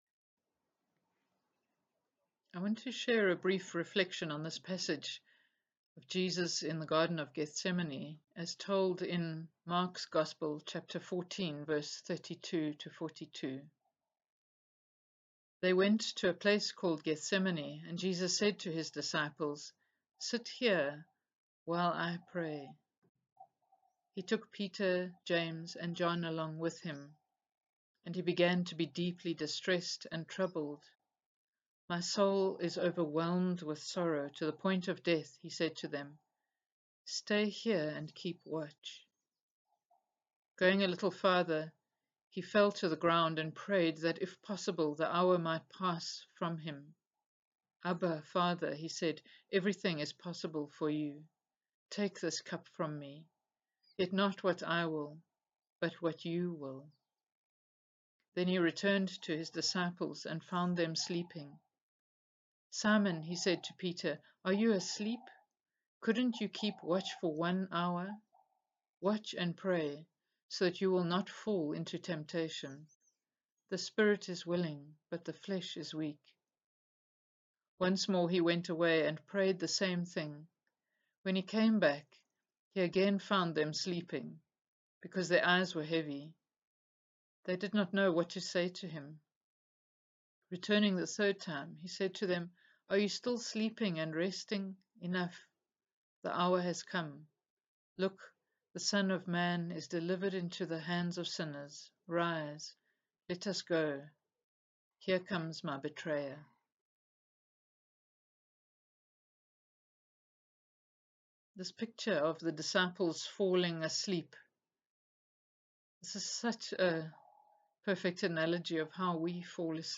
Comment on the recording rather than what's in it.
The following video provides a brief reflection on this scripture passage, which is followed by a time of prayer accompanied by images and music: